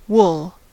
wool: Wikimedia Commons US English Pronunciations
En-us-wool.WAV